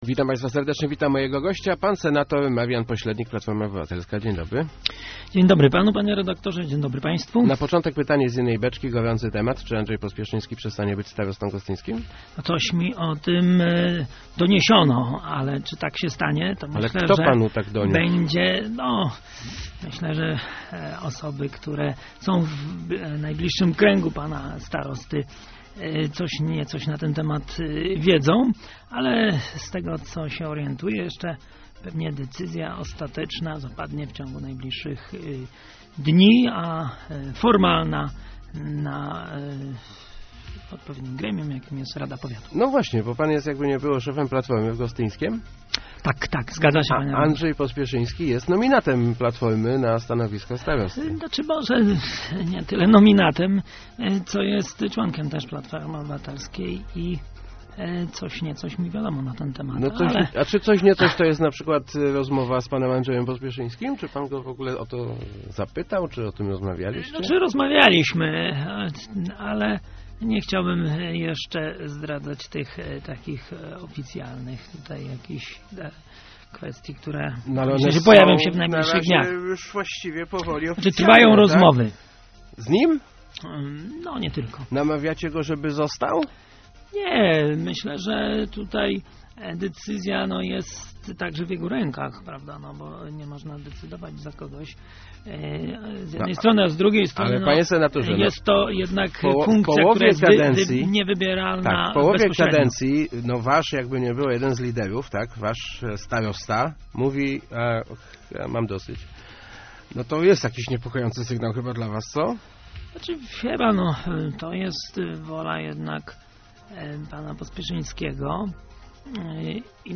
marpolsednik.jpgRezygnacja starosty Andrzeja Pospieszyńskiego to będzie jego suwerenna decyzja - mówił w Rozmowach Elki senator Marian Poślednik, szef PO w powiecie gostyńskim. Przyznał jednak, że ugrupowanie już się na nią przygotowało; trwają rozmowy z ewentualnym następcą.